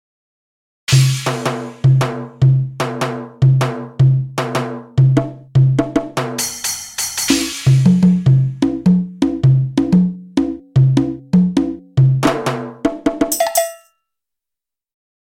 Play, download and share Real percussion original sound button!!!!
real-percussion.mp3